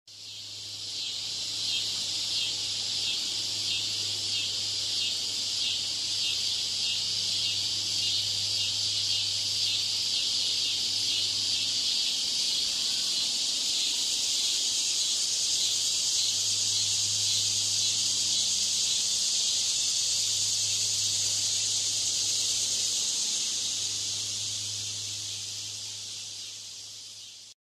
Cicada Sound Effect Free Download
Cicada